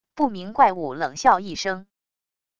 不明怪物冷笑一声wav音频